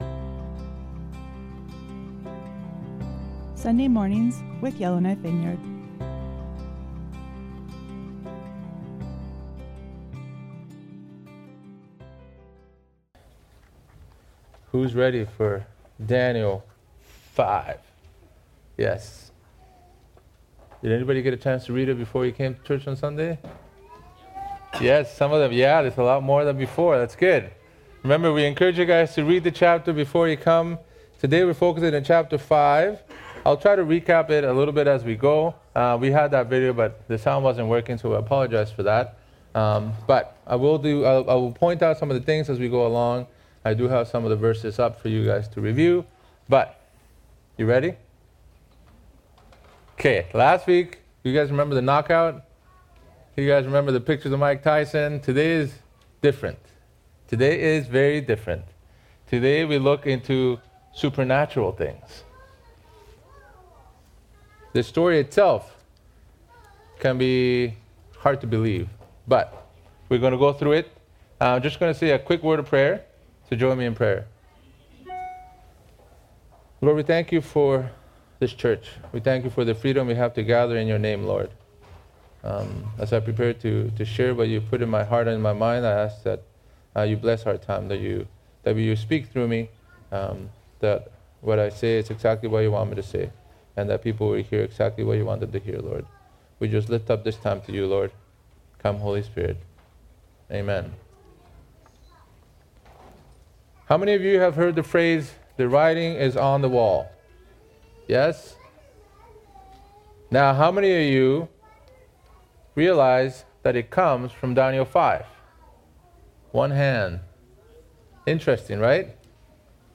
Sermons | Yellowknife Vineyard Christian Fellowship